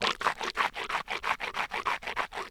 Human Male Rinses Mouth With Water